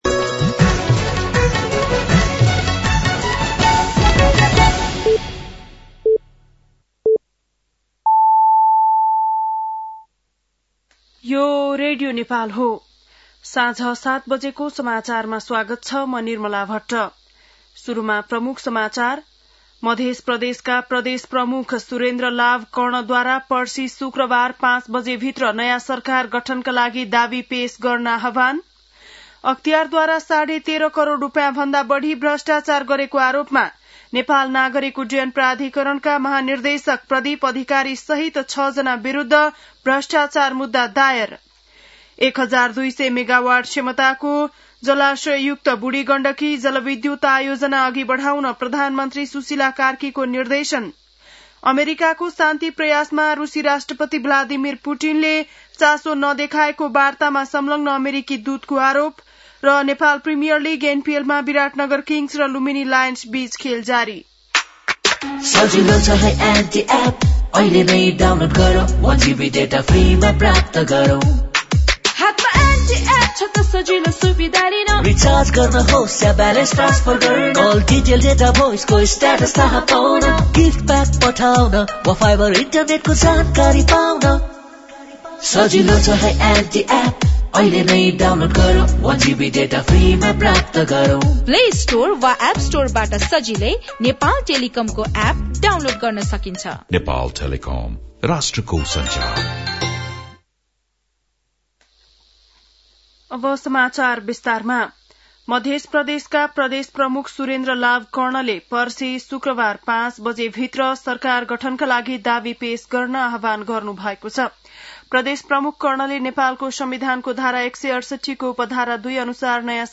An online outlet of Nepal's national radio broadcaster
बेलुकी ७ बजेको नेपाली समाचार : १७ मंसिर , २०८२